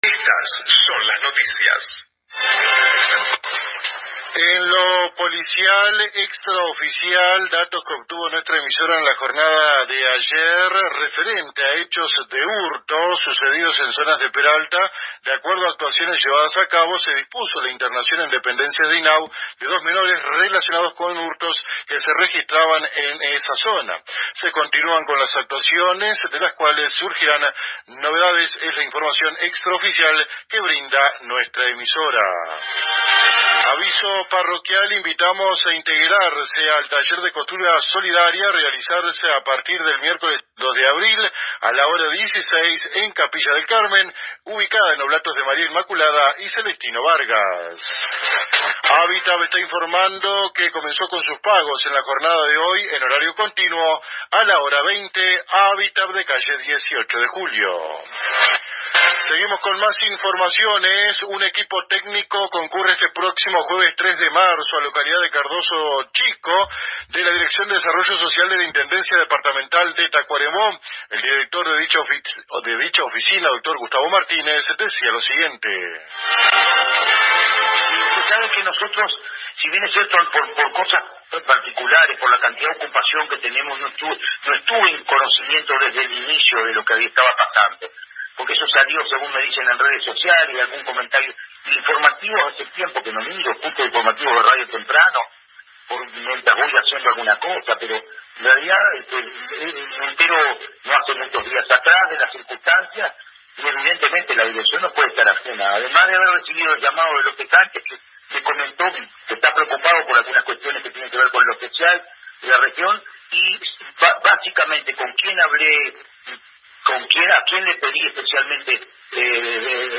Así lo dijo el Director de esta dependencia, Gustavo Martínez, en el Noticiero Central de la AM 1110 de nuestra ciudad, acotando que los funcionarios del área social y de construcción estarán en la referida localidad a partir de las 8:30 horas del jueves próximo para evaluar los daños en casas y la escuela, entre otras necesidades en las que puedan apoyar, y así buscar solución para que las viviendas y el local educativo vuelvan a funcionar como antes.